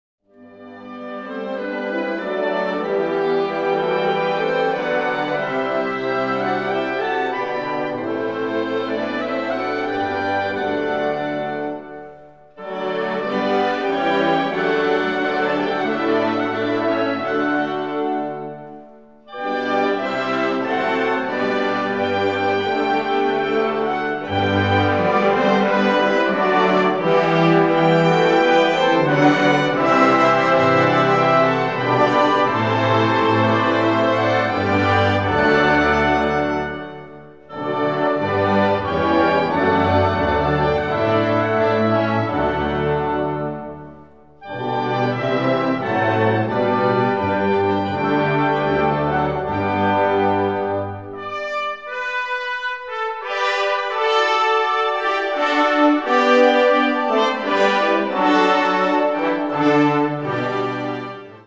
Categoria Concert/wind/brass band
Sottocategoria Suite
Instrumentation Ha (orchestra di strumenti a faito)